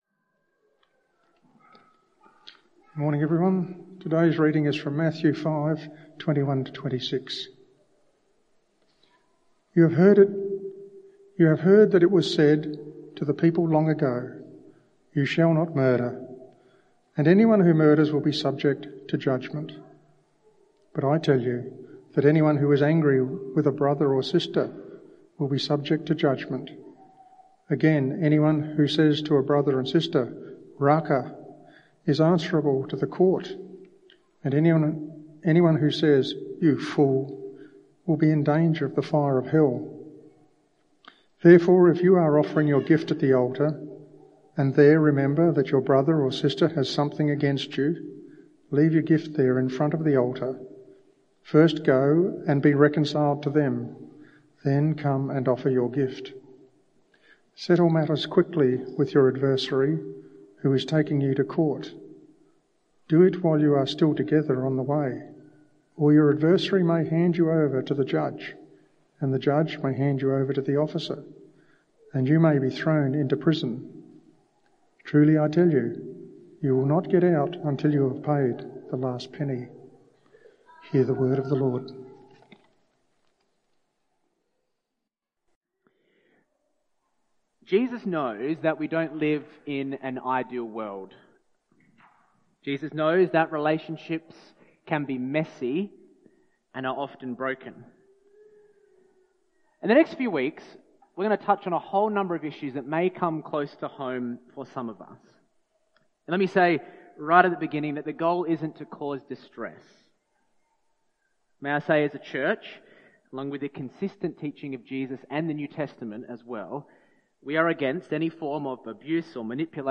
This Bible talk focuses on Jesus’ teaching about anger, reconciliation, and the value of human relationships in Matthew 5.